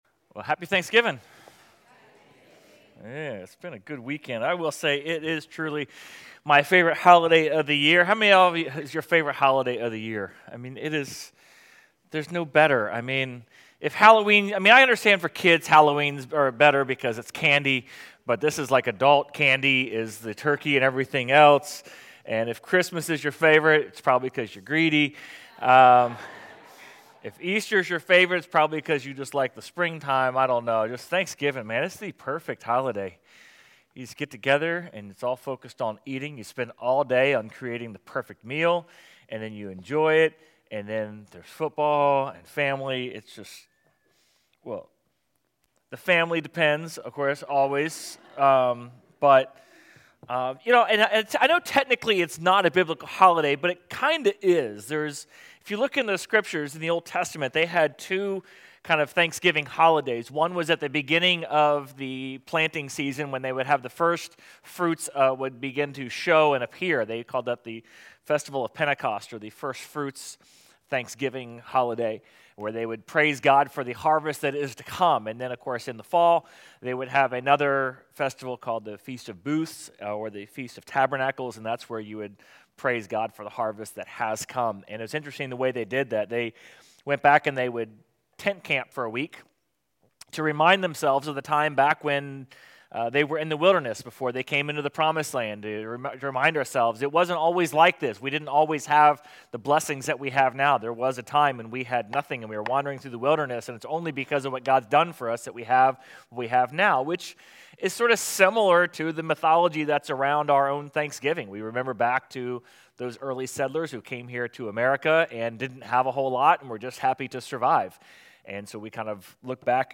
Sermon Audio/Video | Essential Church